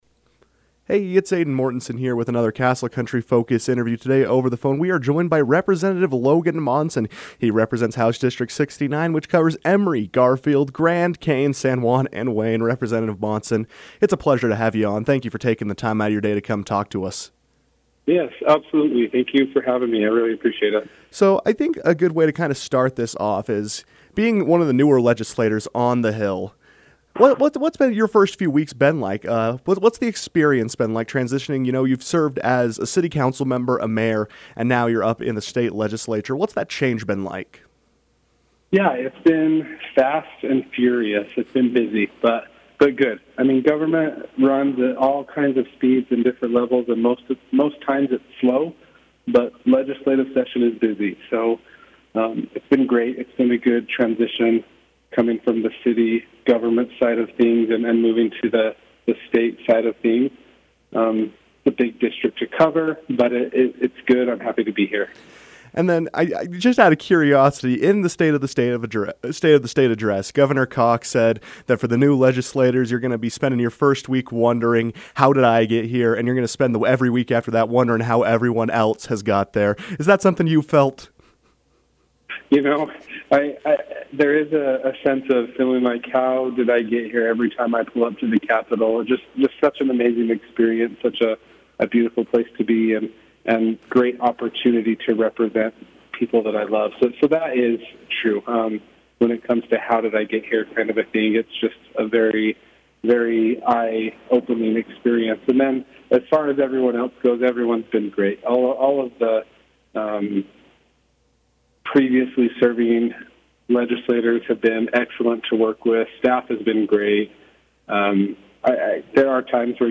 Rep. Monson called into the KOAL newsroom to discuss his first weeks in the legislature as well as preview what his points of emphasis for future legislation might look like.